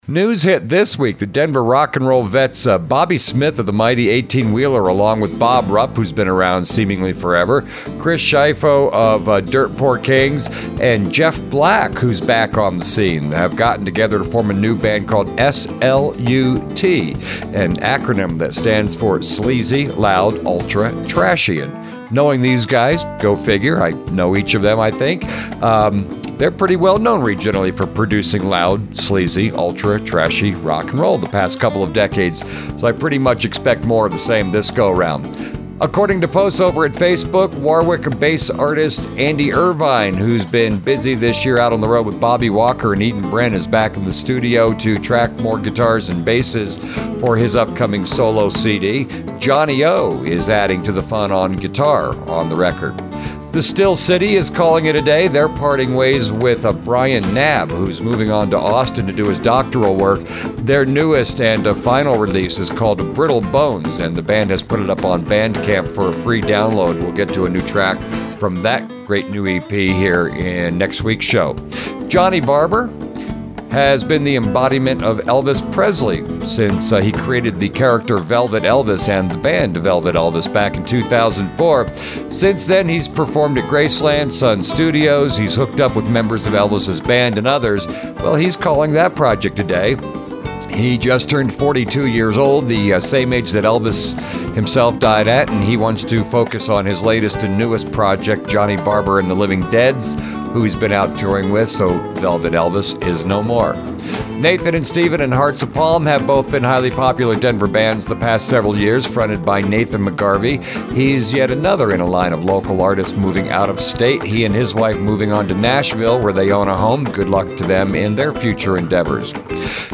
NEWS & NOTES